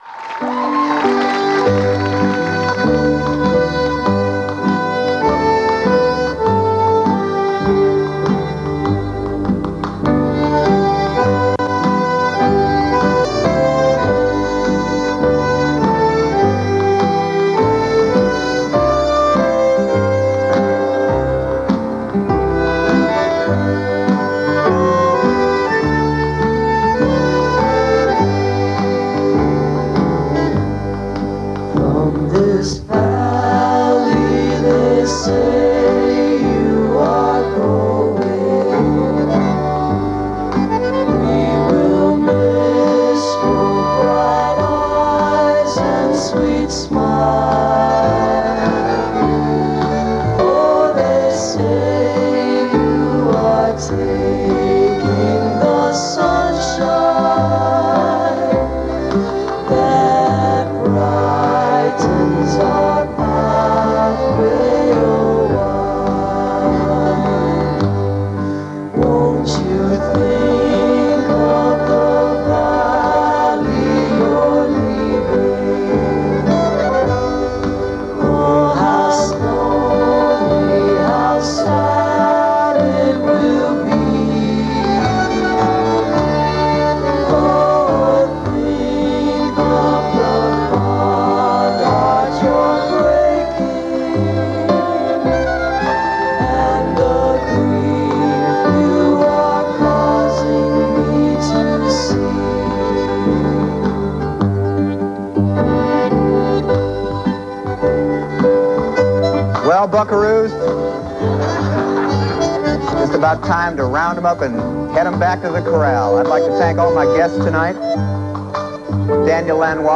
(audio capture from a video)